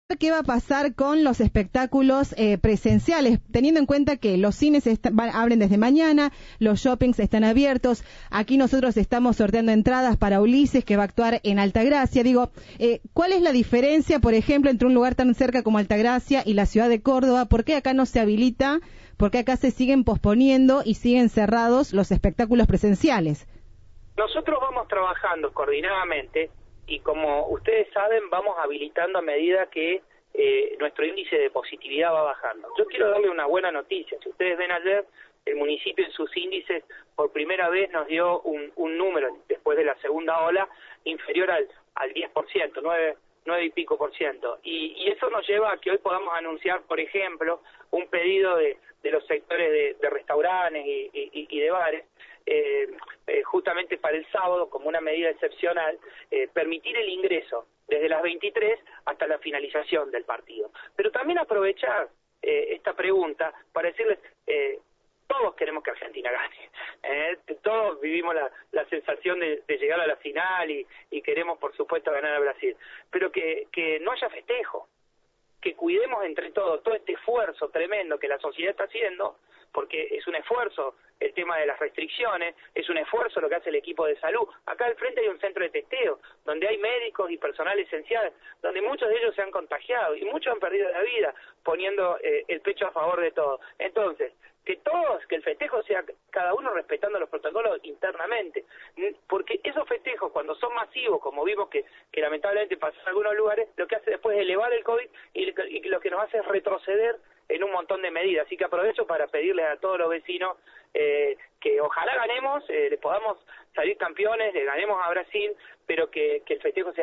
Entrevista de Viva la Radio.